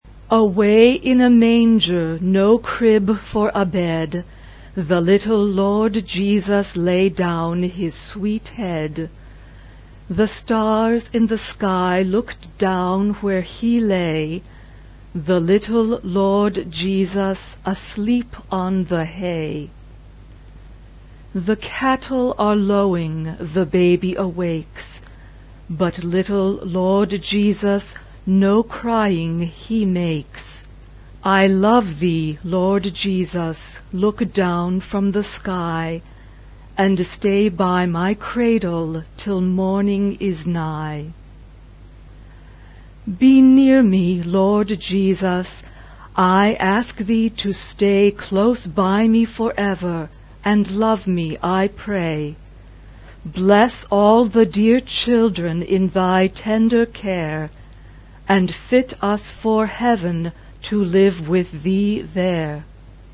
SSA (3 voices women OR children) ; Full score.
Tonality: G major